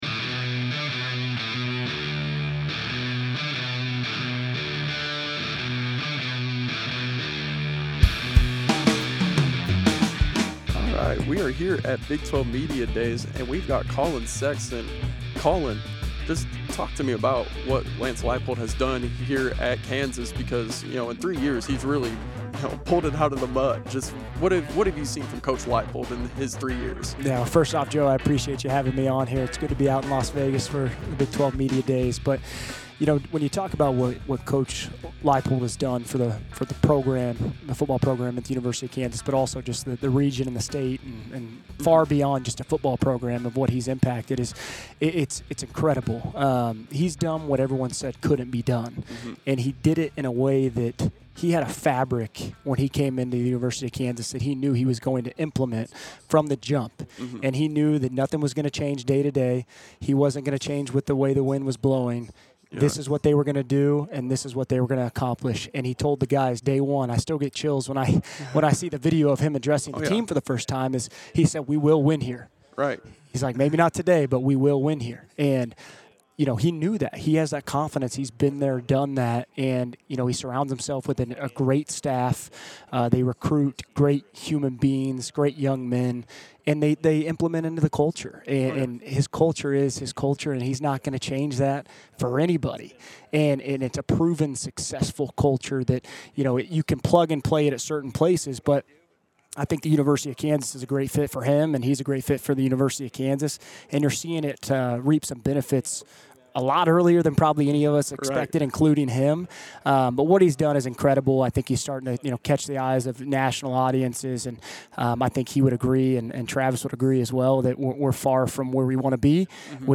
Big 12 Media Days
Las Vegas, NV – Western Kansas News sports staff spent this week at the Big 12 Media Days covering the Kansas State Wildcats and the Kansas Jayhawks, as well as acquiring exclusive interviews with other programs and members of the media.